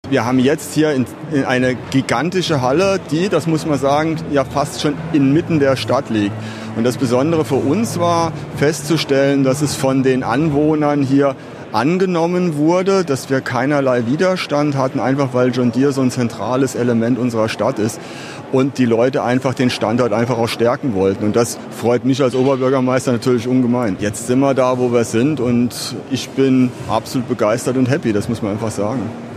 Der Landmaschinenhersteller John Deere hat am Mittwoch sein neues Logistikzentrum in Zweibrücken eingeweiht. Oberbürgermeister Wosnitza (SPD) sagt, er sei absolut begeistert.